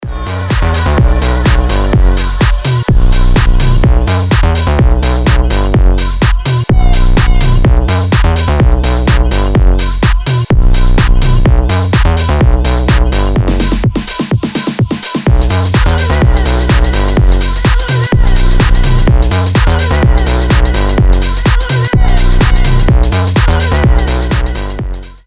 • Качество: 320, Stereo
громкие
мощные
Electronic
EDM
мощные басы
Bass House
Крутой bass house